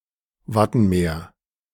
; German: Wattenmeer [ˈvatn̩ˌmeːɐ̯]
De-Wattenmeer.ogg.mp3